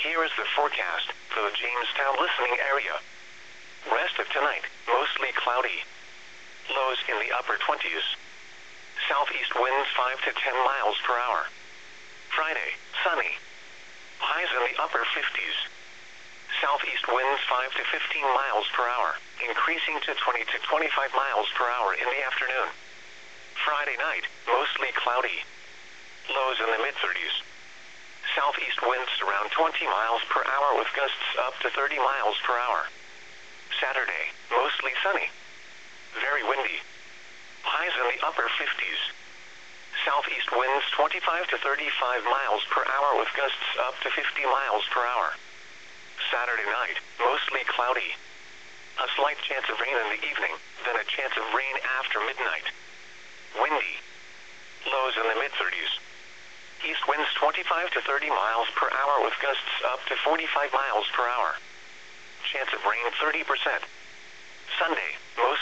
NOAA氣象預警廣播的錄音好了,這玩意就跟普通FM廣播一樣,外加有緊急狀態時NOAA可以發送信號讓聽眾的接收機顯示燈號或發出警示音,所以不想聽的人大可把聲音關掉,但該響的時候它還是會響,也因為和普通FM廣播一樣,不必經過一堆很複雜的機制才能到達受眾端,所以可靠度自然也高多了(啥,你說你在的位置收不到,那麻煩你自己拉天線到收得到的地方吧),但也由於先天上結構比較簡單,就無法做到透過行動網路發送可以做比較精細的控制(像是鵝有收過天龍國某區有強降雨/雷雨的警報,不在此區域的朋友就沒收到),只適合發送大區域的預警,但這對台灣而言問題應該不大就是了.....
NOAA-WX.wav